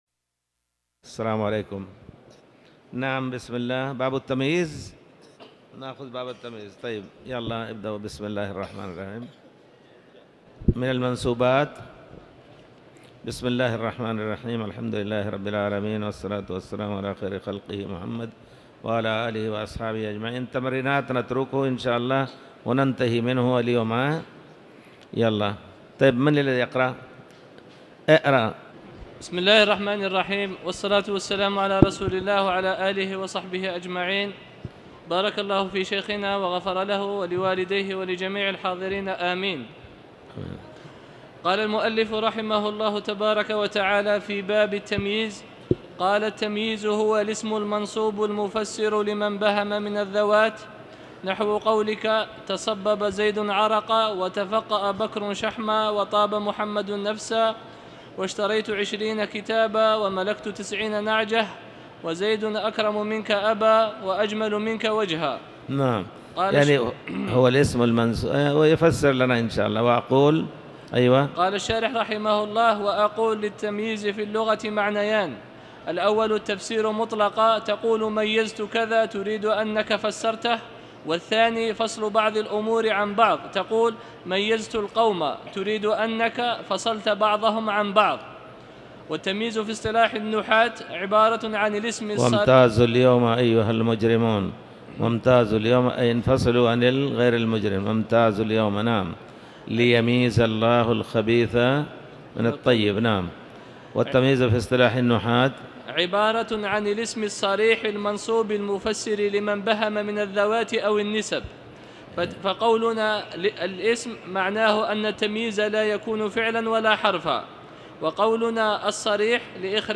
تاريخ النشر ٢٠ جمادى الأولى ١٤٤٠ هـ المكان: المسجد الحرام الشيخ